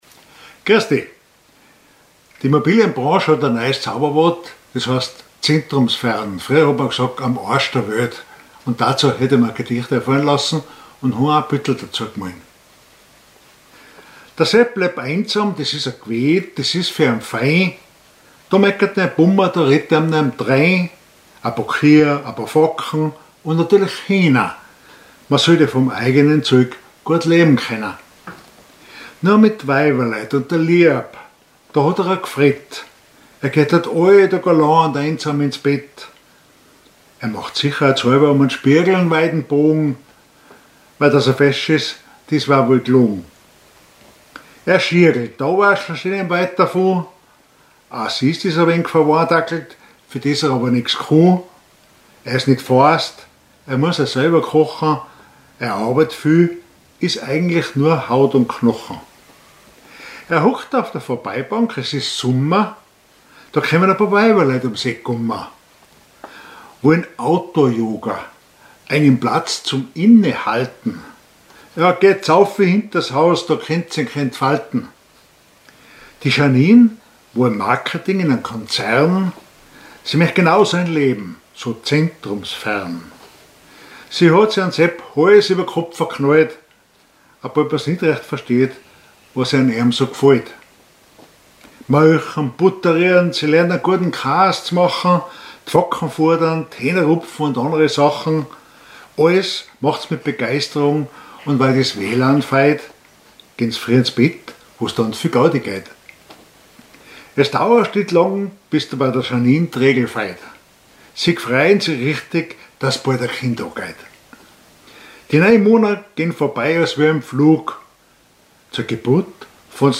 Mundart
Gedicht Monat Oktober 2024